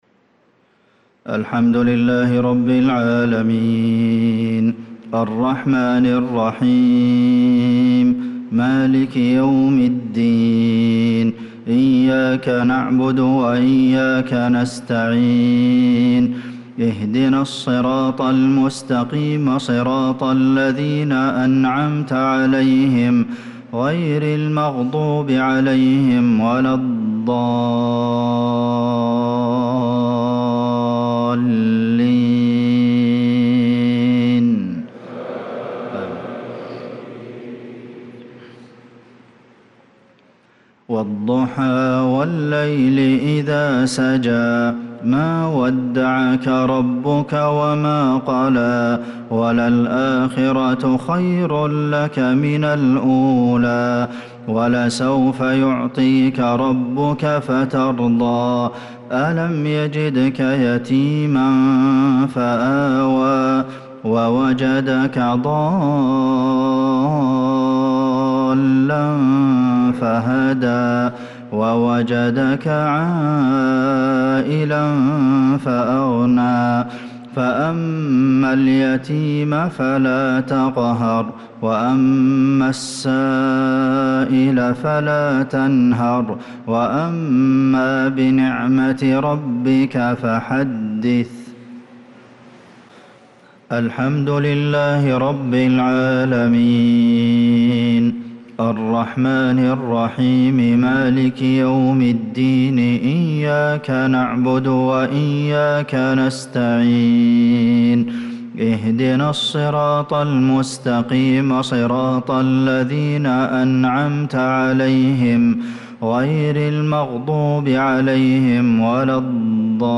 صلاة المغرب للقارئ عبدالمحسن القاسم 4 ذو الحجة 1445 هـ
تِلَاوَات الْحَرَمَيْن .